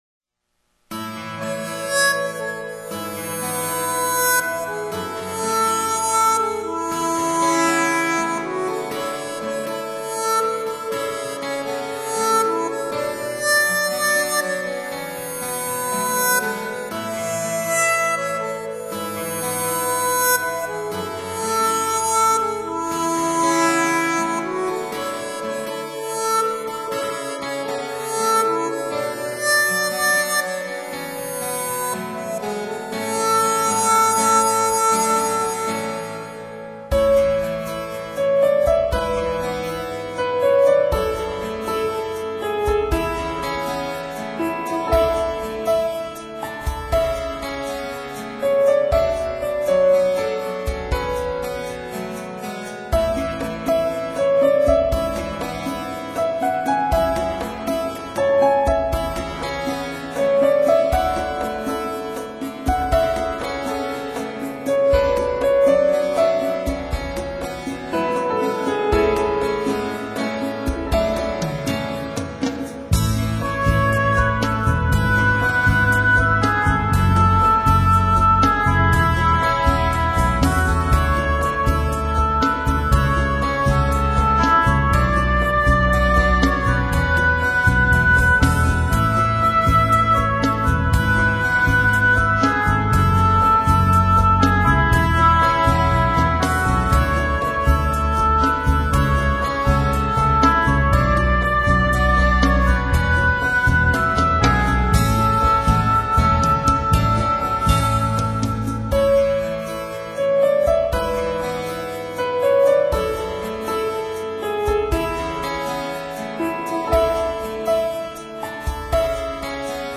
佛曲系列
口琴传奇